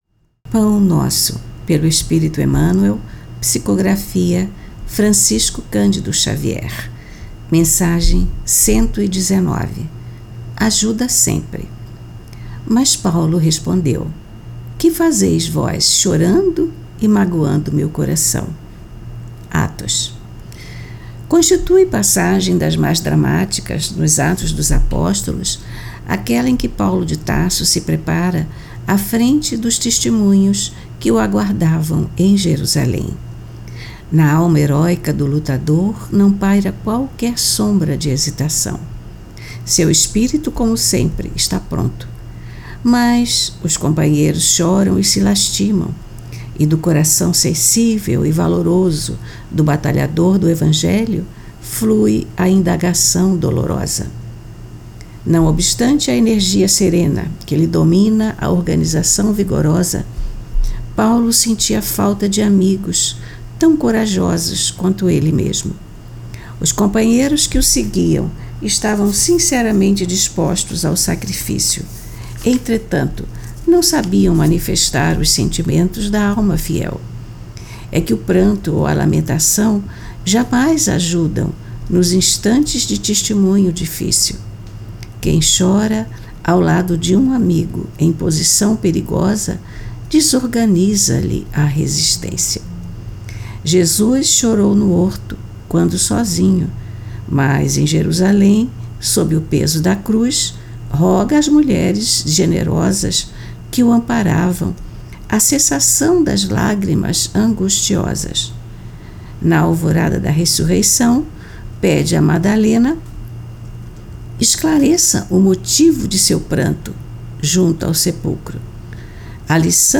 Leitura e comentários do livro: Pão Nosso, pelo espírito Emmanuel, psicografia de Francisco Candido Xavier.